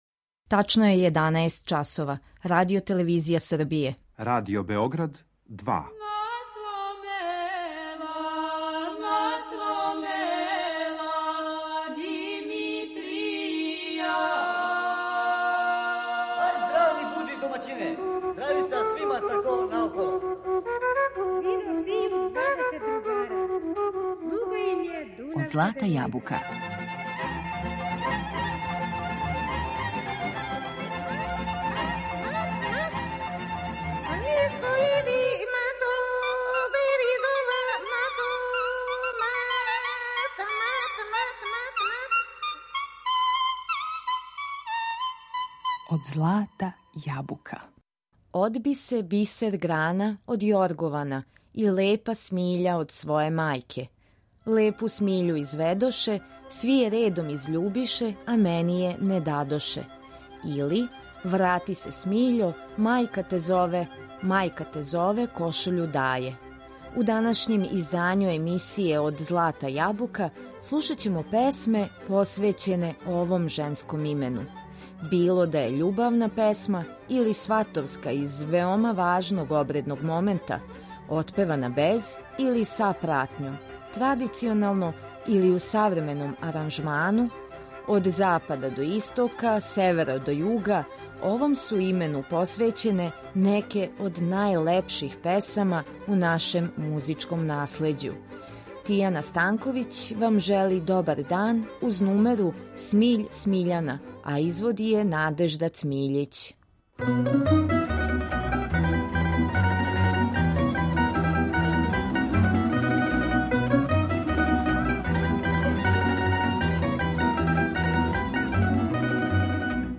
Осим различитих варијанти ове нумере која се данас ретко изводи у оквиру свадбеног церемонијала, на репертоару су и савремене обраде традиционалних песама посвећених Смиљани и компоноване песме у народном духу.